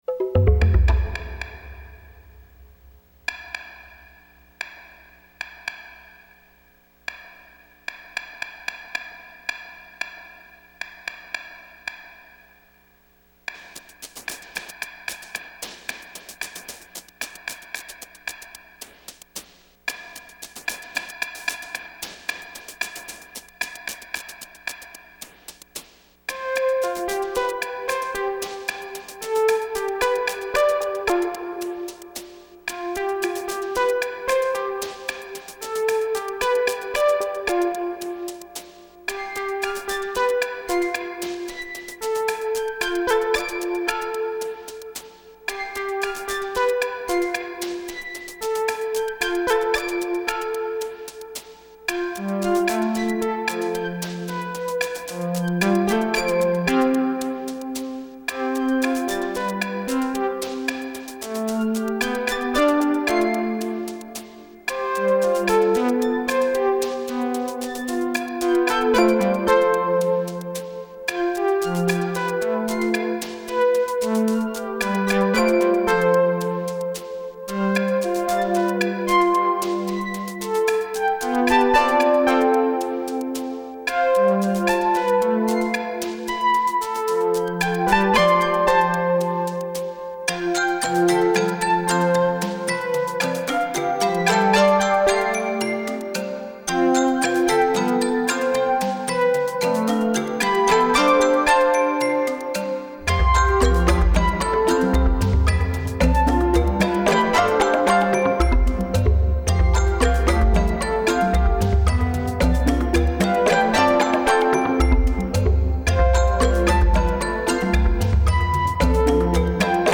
Operating Instructions This came about by restricting myself to only using one MIDI track and one channel with only Note On & Off data plus a loop point. Using an Oberheim Xpander, a Roland MKS-80, a Kurzweil 250 and a Casio CZ-5000. Each voice was programmed to respond differently to the same performance, making it sound like multiple tracks.